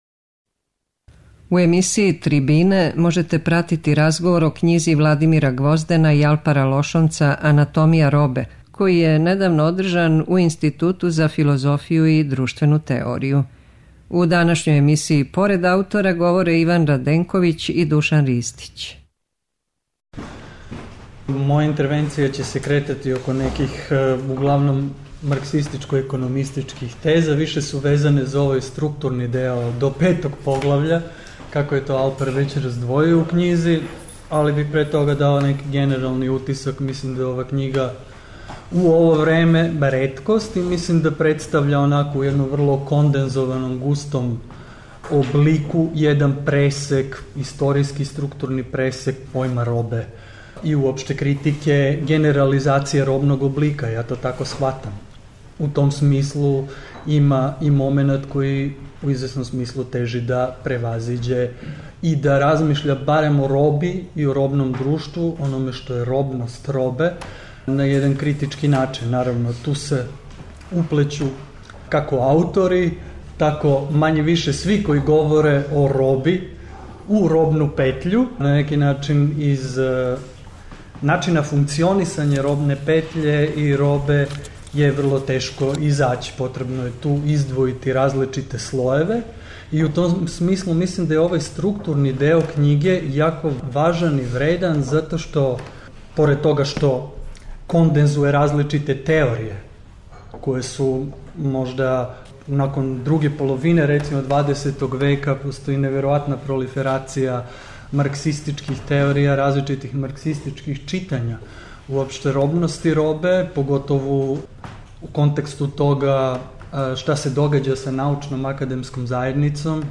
преузми : 13.32 MB Трибине и Научни скупови Autor: Редакција Преносимо излагања са научних конференција и трибина.